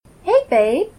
UPDATE: Due to feedback that the background noise of the files was too great, I have gone ahead and added a REDUCED VOLUME edition – with this, I did a flat normalization of all the files in audacity. Please note, I cannot eliminate all of the background noise, but this is an option for those of you with more sensitive ears.